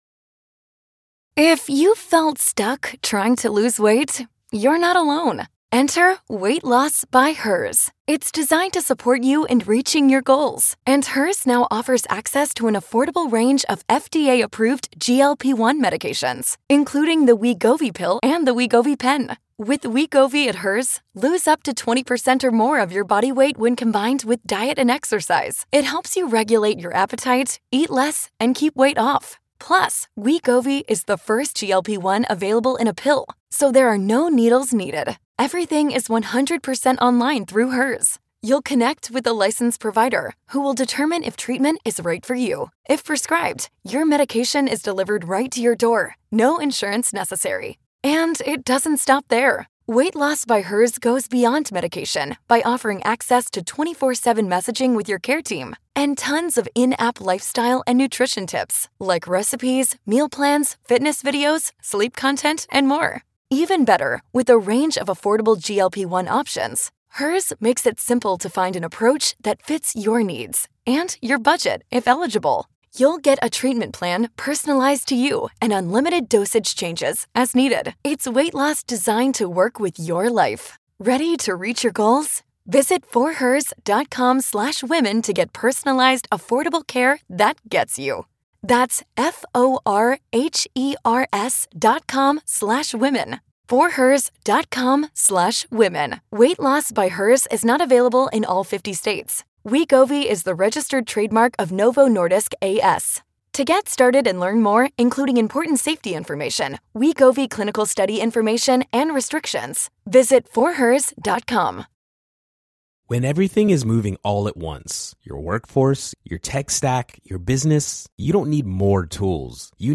Rushkoff sits down with Taylor Lorenz, the internet’s premier culture reporter and founder of User Mag, to discuss her departure from legacy media (The New York Times, The Washington Post) and the dangerous reality of the new tech authoritarianism.